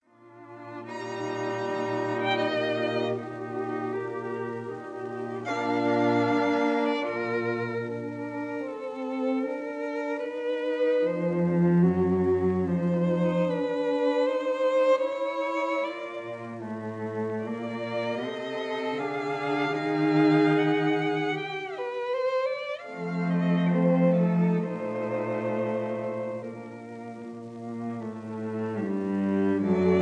violins
viola
cello
in B-flat major — Largo